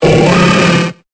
Cri de Kicklee dans Pokémon Épée et Bouclier.